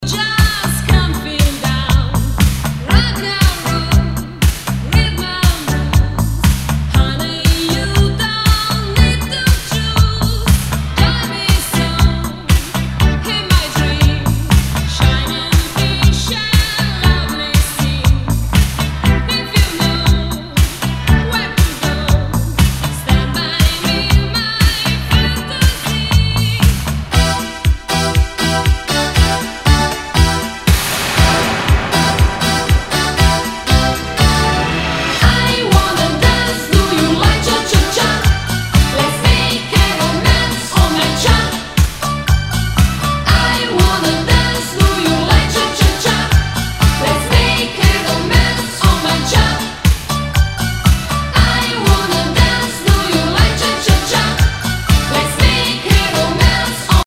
SOUL/FUNK/DISCO
ナイス！シンセ・ポップ・ディスコ！